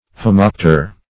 homopter - definition of homopter - synonyms, pronunciation, spelling from Free Dictionary Search Result for " homopter" : The Collaborative International Dictionary of English v.0.48: Homopter \Ho*mop"ter\, n. (Zool.) One of the Homoptera .